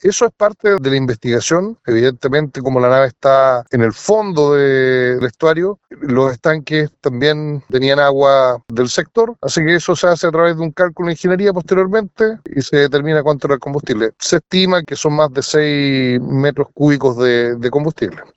Desde la Armada, el mando en la zona explicó que se tienen que realizar otros análisis para tener claridad de la cantidad de combustible, aunque se estima que son alrededor de 6 metros cúbicos, es decir, unos 6 mil litros los extraídos.